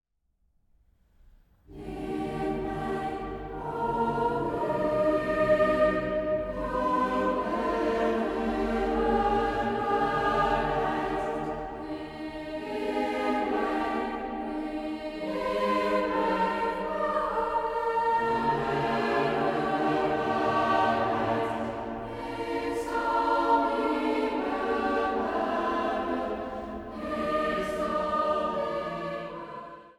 Kerkmuziek door de eeuwen heen
hoofdorgel
koororgel
blokfluit.
Zang | Jongerenkoor